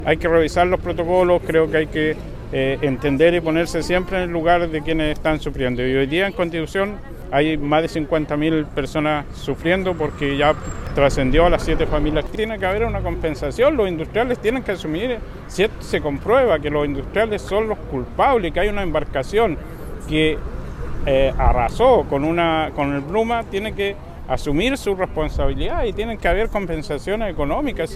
El alcalde de Constitución, Carlos Valenzuela, fue más allá y dijo que de comprobarse una colisión protagonizada por una embarcación industrial, deben existir sanciones no solo penales, sino que también civiles.